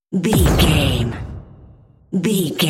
Dramatic hit slam door rvrb
Sound Effects
heavy
intense
dark
aggressive
hits